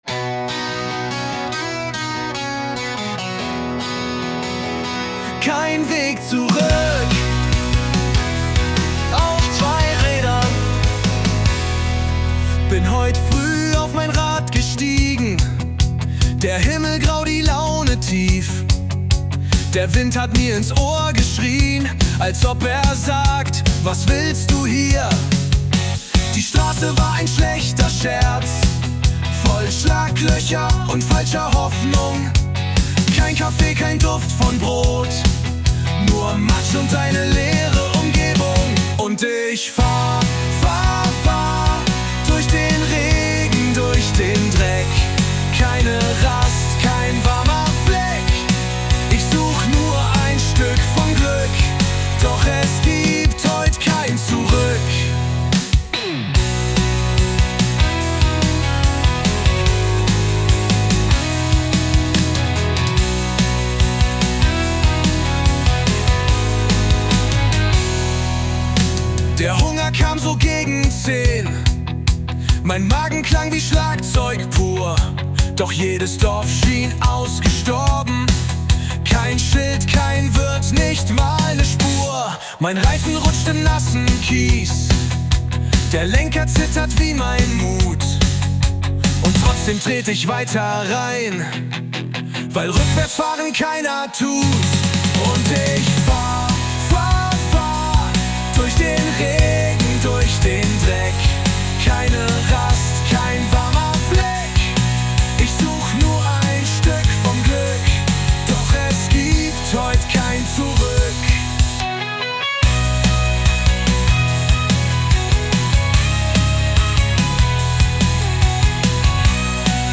Der Liedtext wurde dann an den Musikgenerator Suno übergeben.